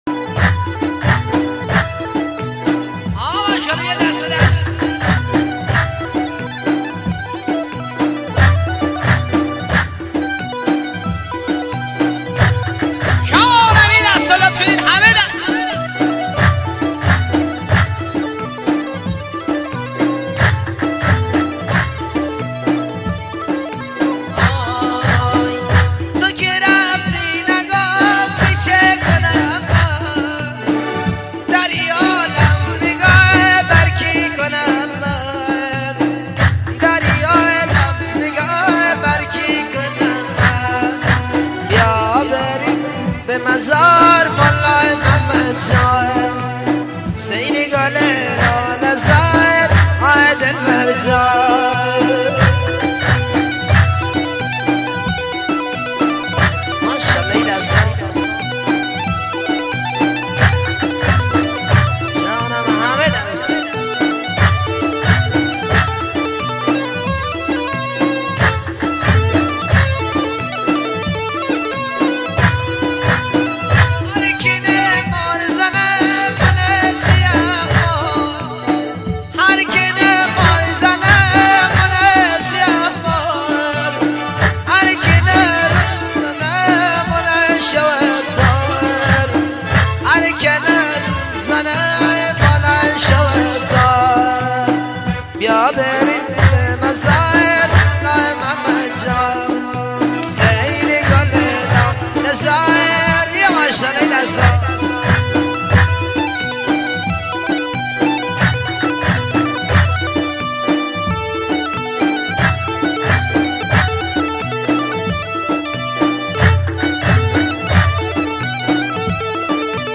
بخش دانلود آهنگ غمگین آرشیو